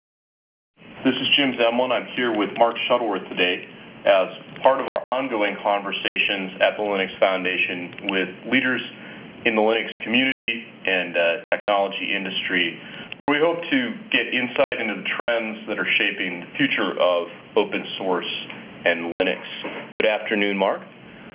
As reference speech fragment the part of Mark Shuttleworth's interview was given.
Then we pass this speech sample through wav2rtp with filter "independent packet losses" turned on and compare source and degraded file with pesqmain utility.
Table 1: Independent network losses influation on the output speech quality (G.729u)